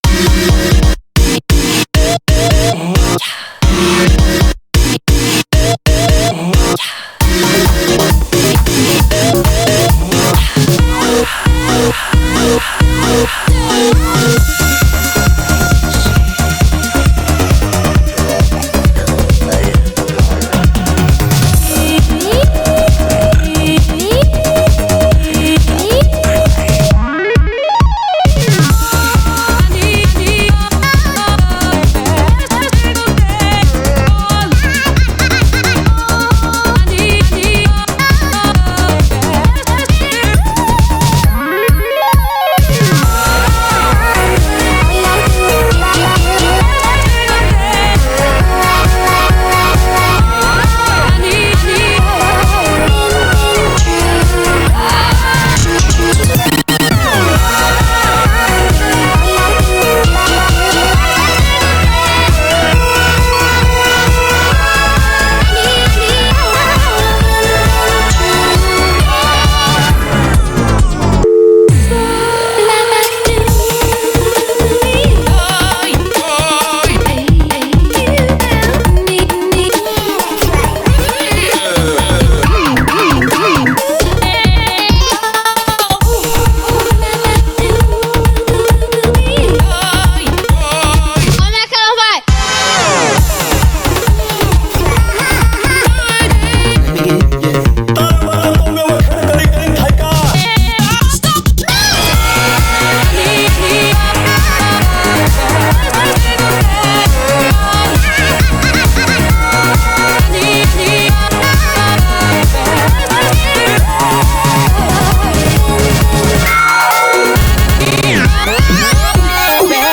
BPM34-134
Comments[ELECTRO]